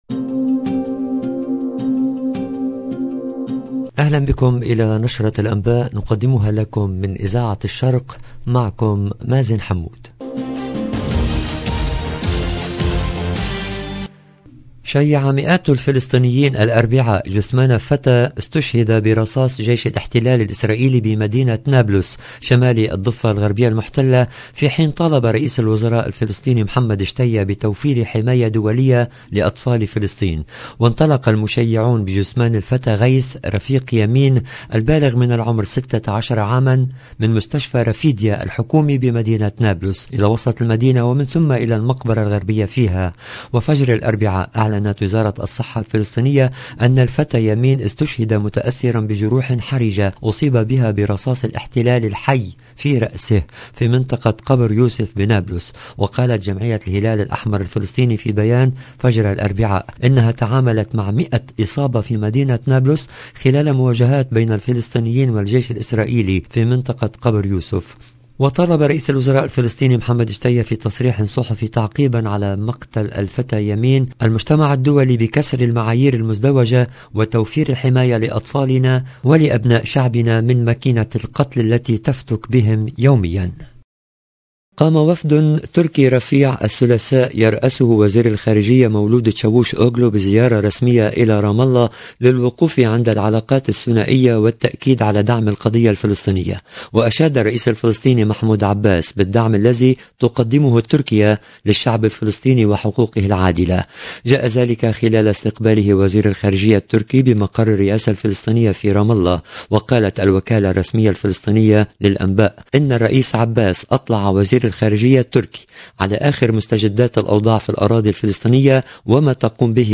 LE JOURNAL DU SOIR EN LANGUE ARABE DU 25/05/22